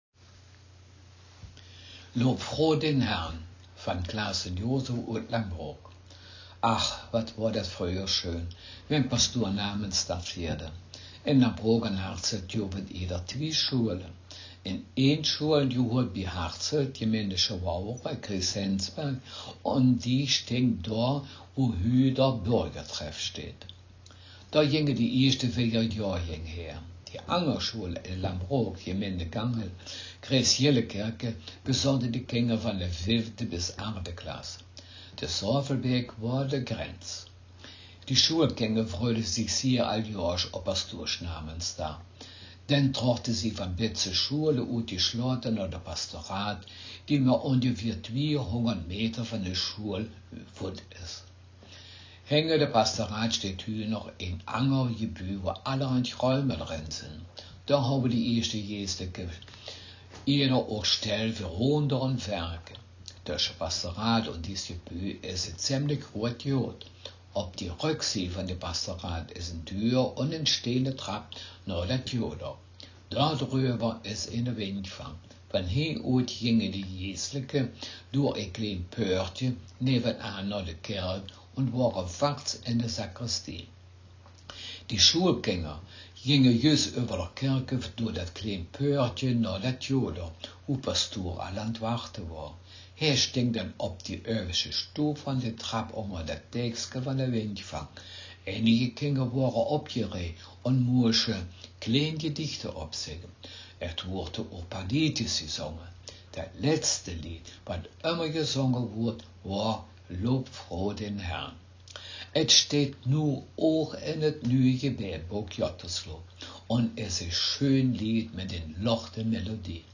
Text Mundart